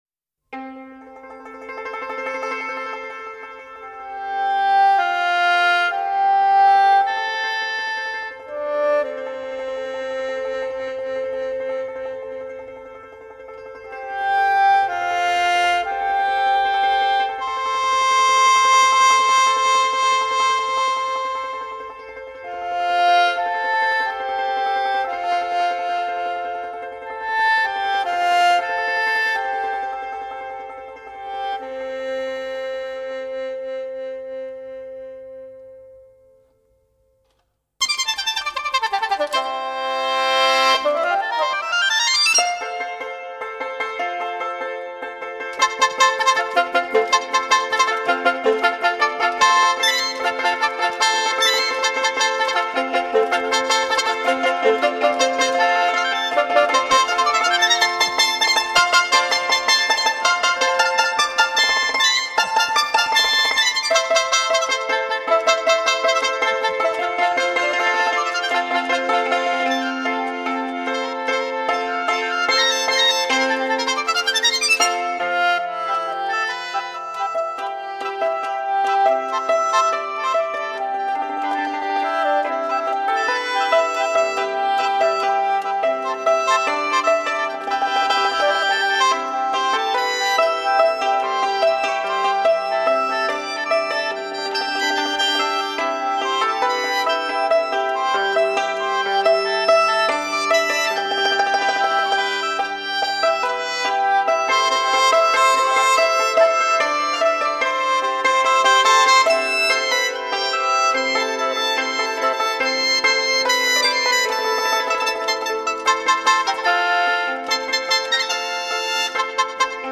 唱片类型：民族音乐
专辑语种：纯音乐